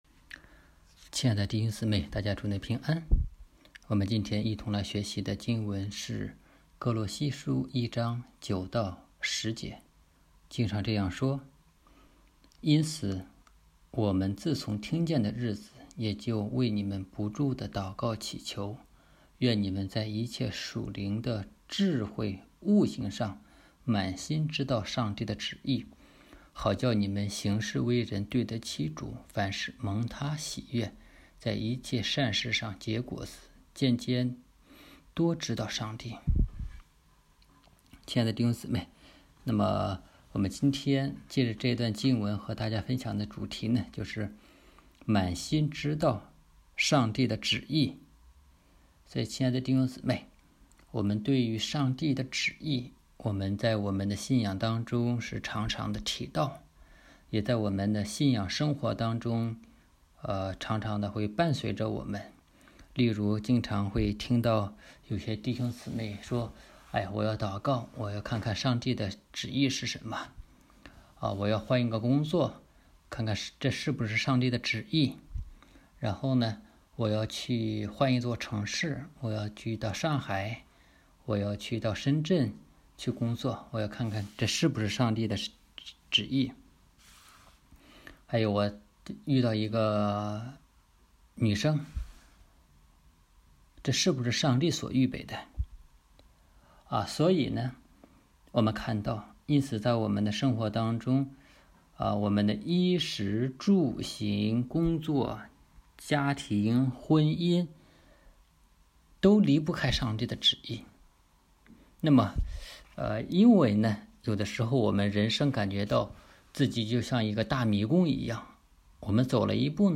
满心知道神的旨意之一》 证道